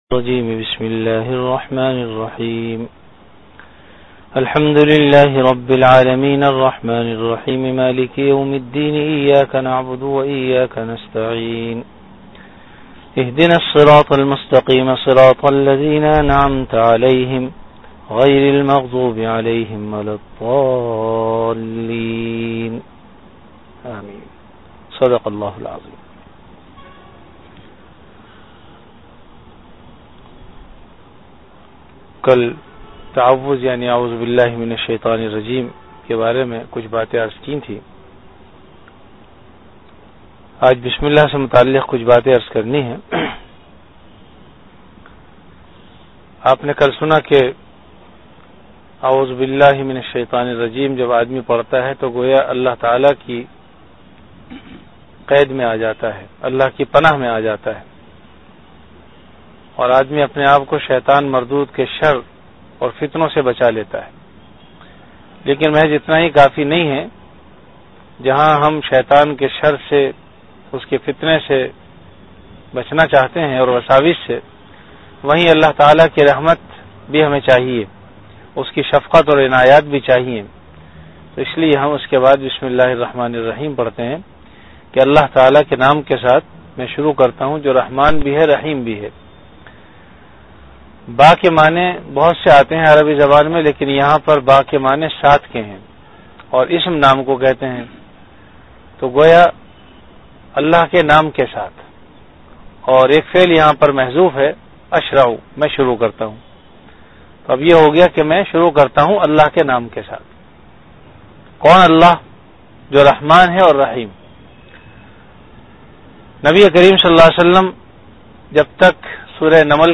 Dars-e-quran
15min Time: After Isha Prayer Venue: Jamia Masjid Bait-ul-Mukkaram, Karachi